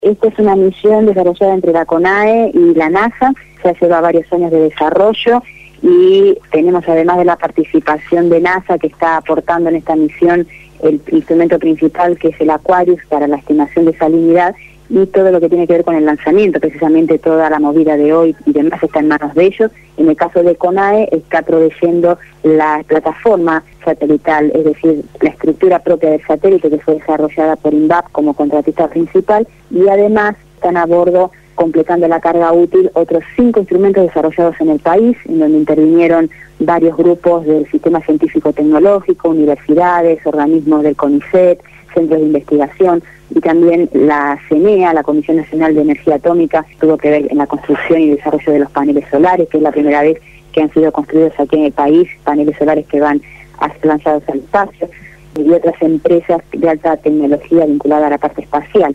habló en Radio Gráfica FM 89.3 la mañana del viernes, antes del lanzamiento del satélite